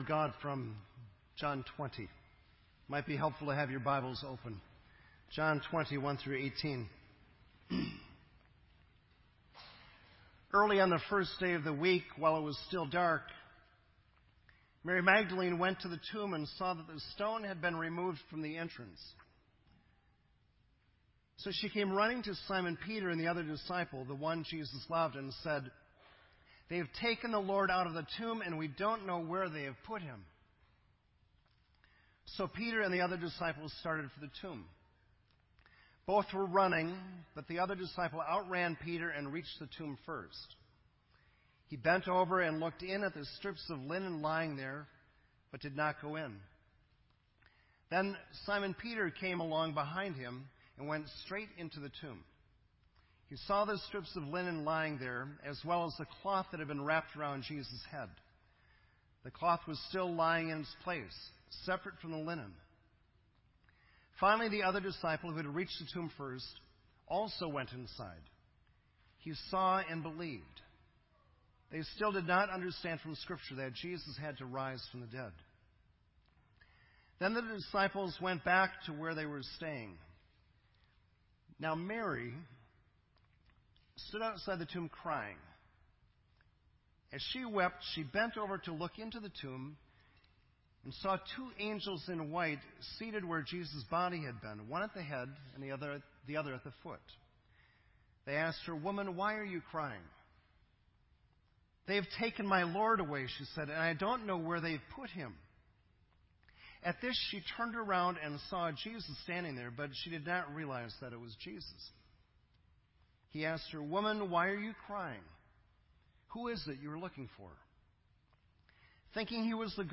This entry was posted in Sermon Audio on March 28